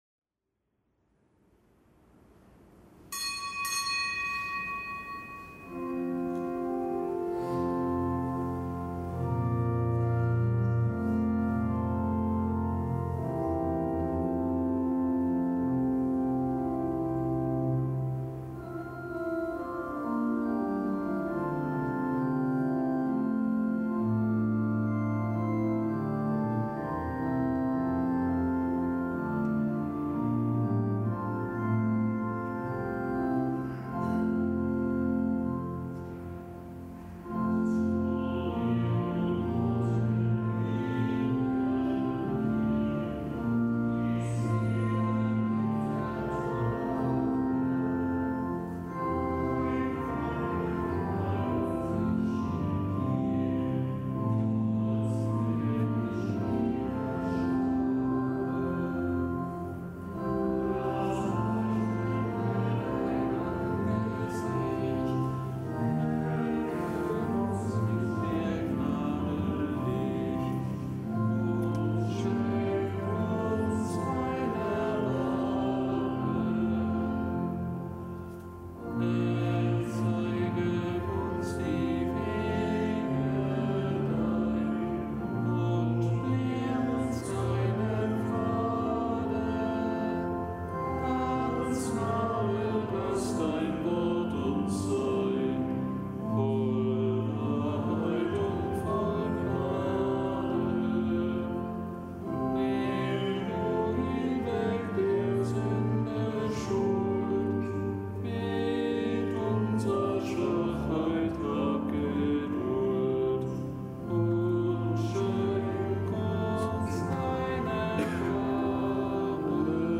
Kapitelsmesse am Montag der fünften Woche im Jahreskreis
Kapitelsmesse aus dem Kölner Dom am Montag der fünften Woche im Jahreskreis.